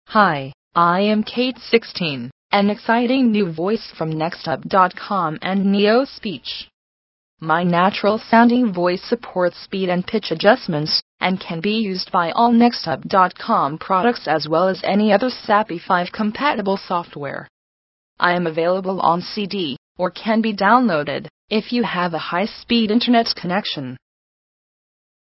We sells the best Text to Speech voices available, represent the cutting edge of TTS Voices.
Kate16 (16khz English)